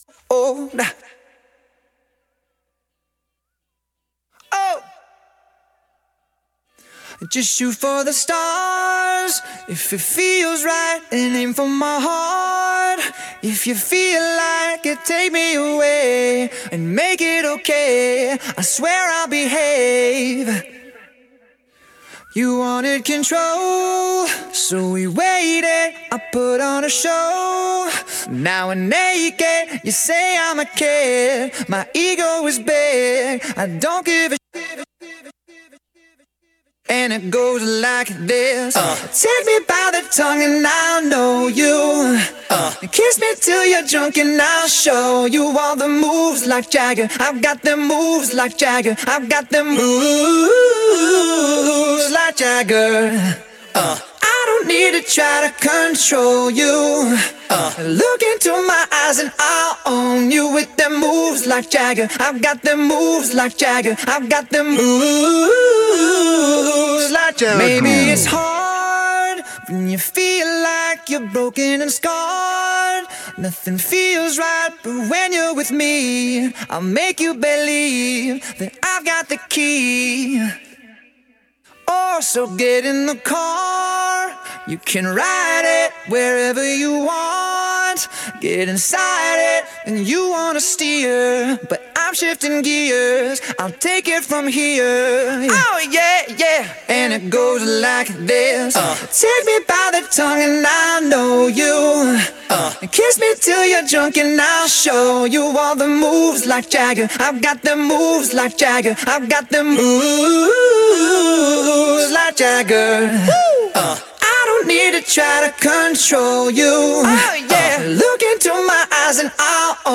Официальная акапелла для вас!